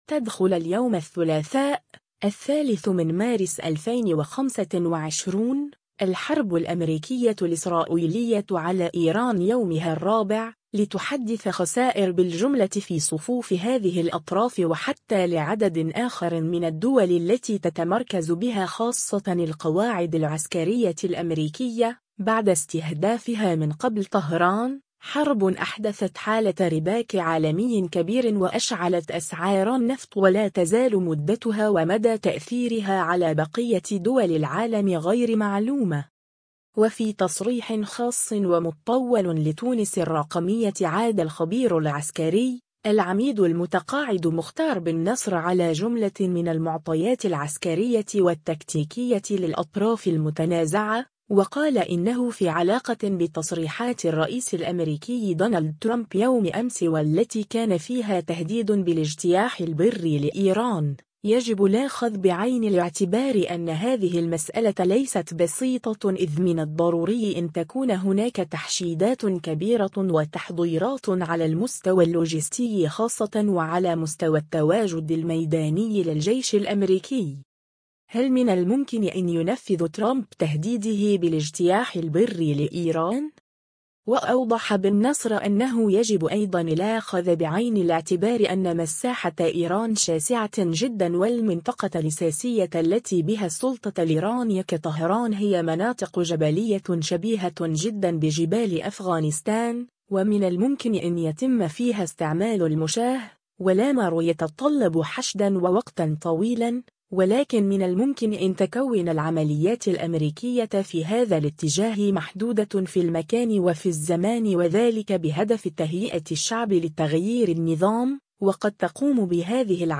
و في تصريح خاص و مطّول لتونس الرّقمية